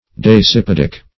Search Result for " dasypaedic" : The Collaborative International Dictionary of English v.0.48: Dasypaedic \Das`y*p[ae]"dic\, a. (Zool.)